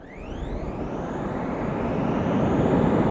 autopilotstart.ogg